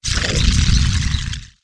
dragon_attack3.wav